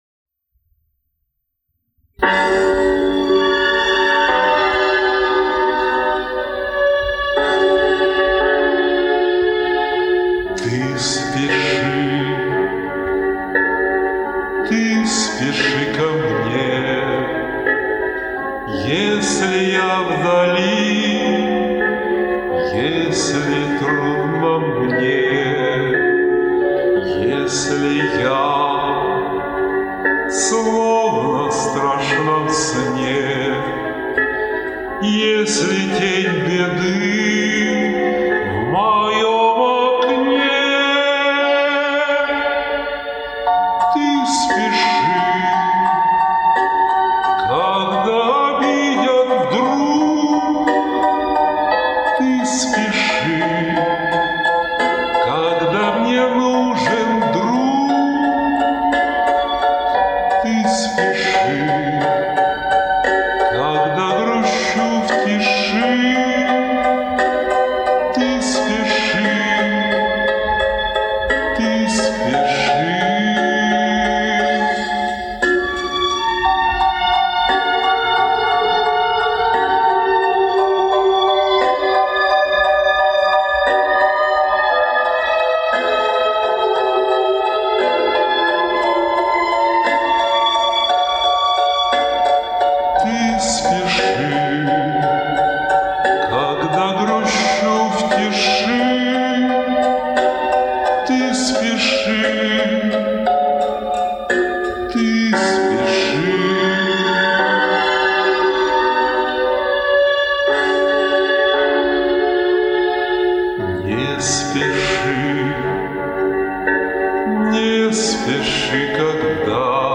- вокальная позиция "плавает"
- ноты вследсвие плывут за ней